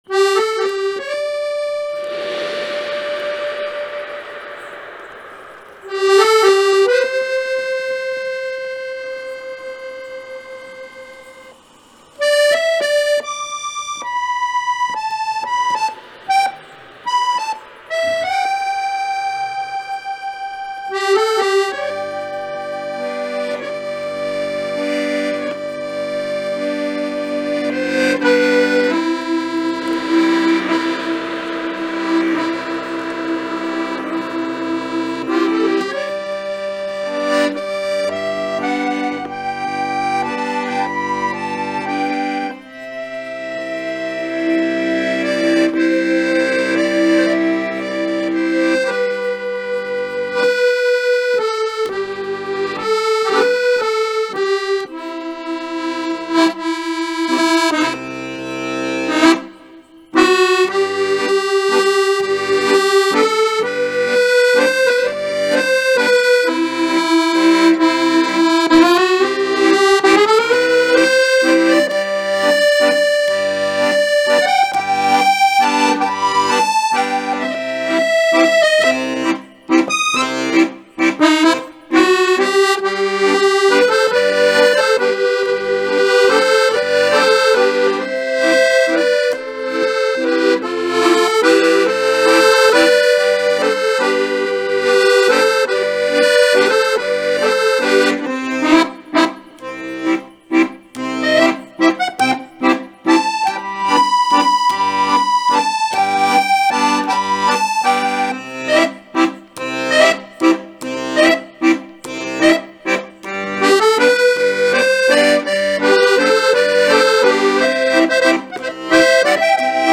Instrumente - Accordion Tempo - Medium Fast BPM - 129